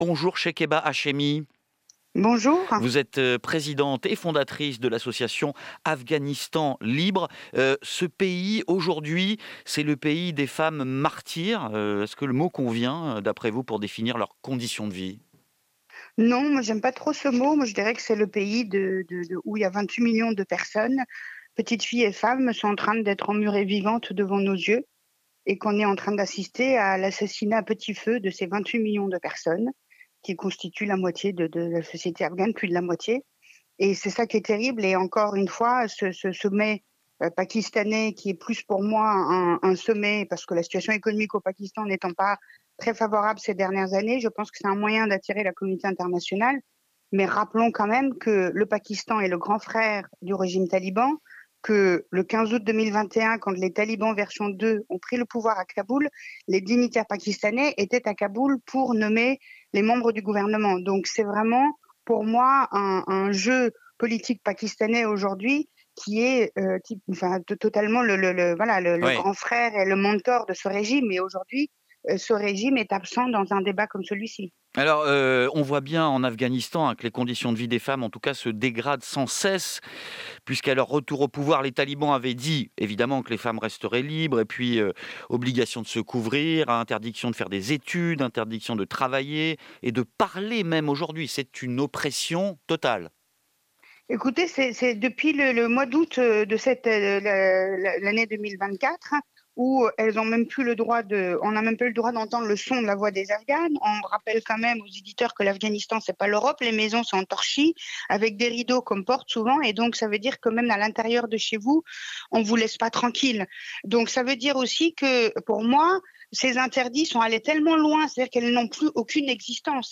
SUMMARY OF THE INTERVIEW